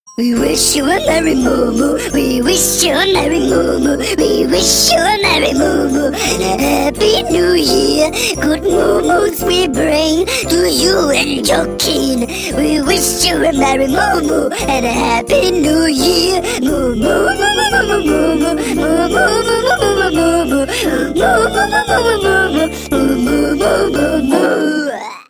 Category: Ringtone